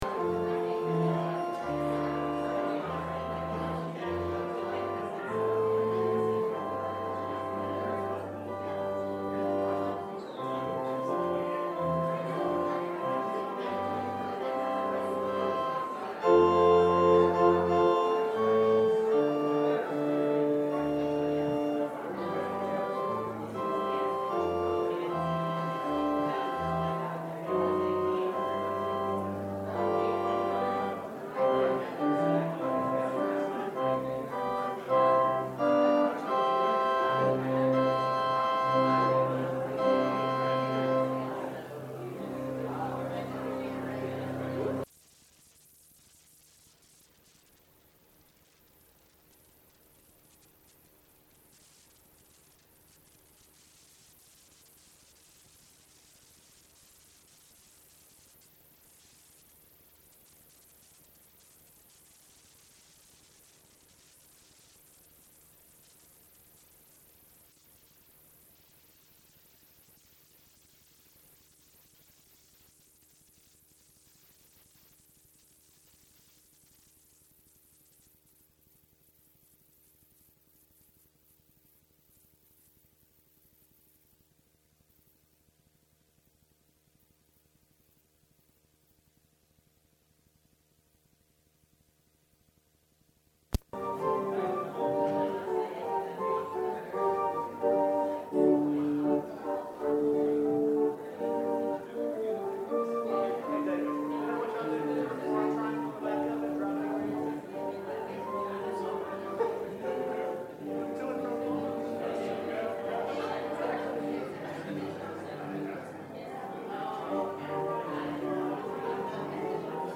Enjoy a Sunday Service with songs of worship being our focus this Christmas season and to ring in the New Year.
December 30, 2018 Ministry in Music Service Type: Sunday Worship Enjoy a Sunday Service with songs of worship being our focus this Christmas season and to ring in the New Year.